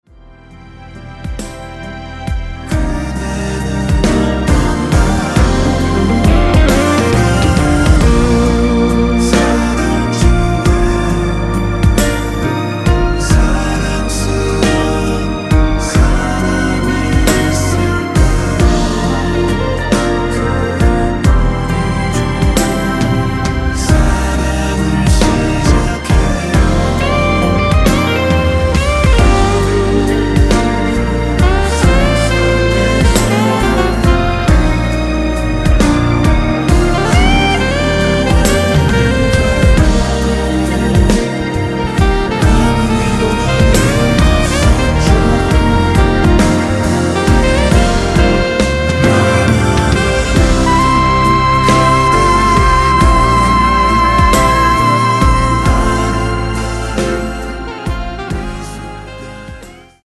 순수 코러스만 들어가 있으며 멤버들끼리 주고 받는 부분은 코러스가 아니라서 없습니다.(미리듣기 확인)
원키에서(-1)내린 코러스 포함된 MR입니다.
Bb
앞부분30초, 뒷부분30초씩 편집해서 올려 드리고 있습니다.
중간에 음이 끈어지고 다시 나오는 이유는